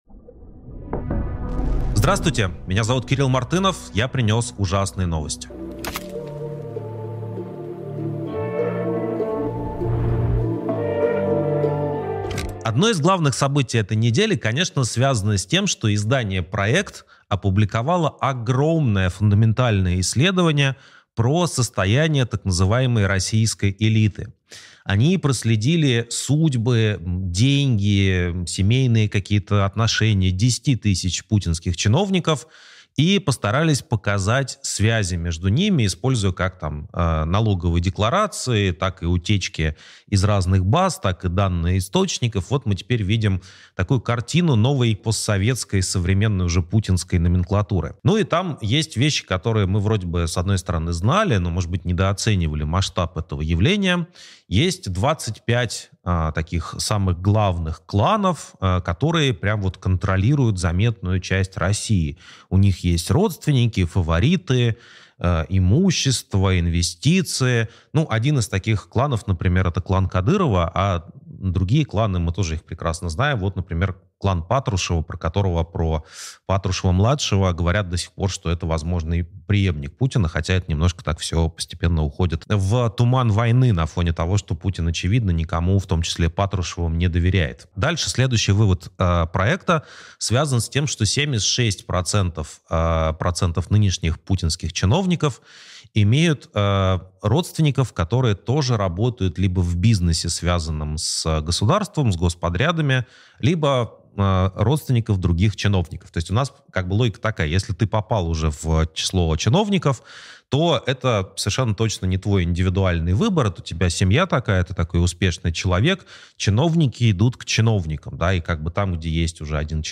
Эфир ведёт Кирилл Мартынов